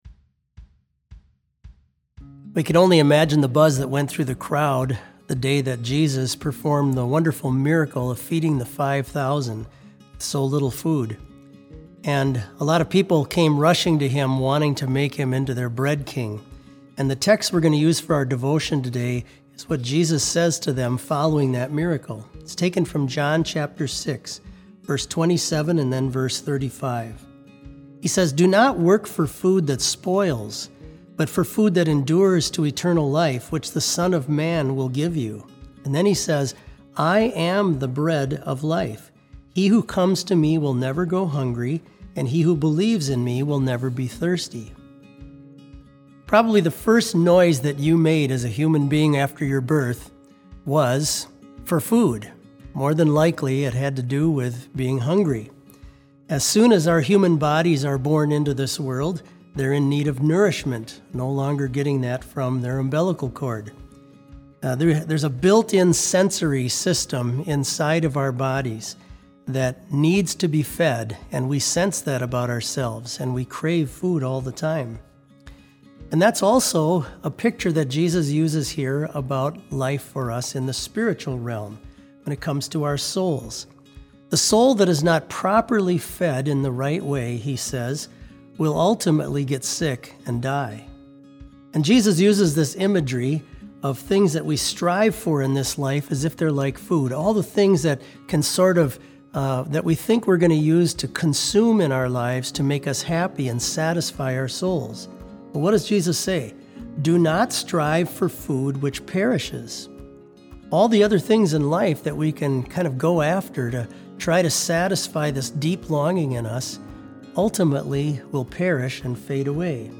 Complete Service
• Devotion
This Special Service was held in Trinity Chapel at Bethany Lutheran College on Thursday, March 26, 2020, at 10 a.m. Page and hymn numbers are from the Evangelical Lutheran Hymnary.